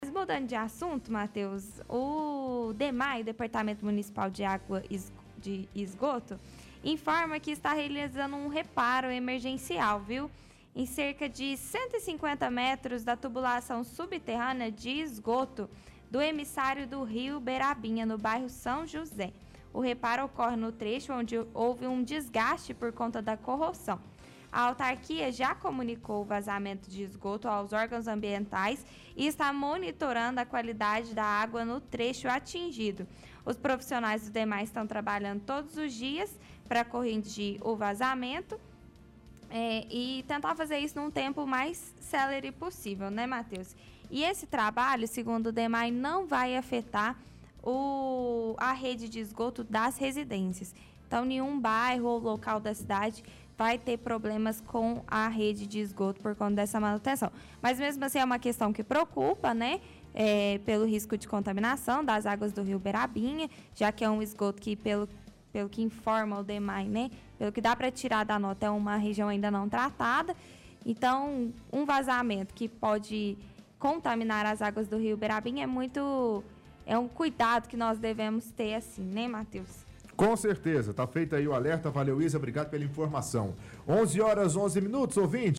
– Leitura de release do site da prefeitura.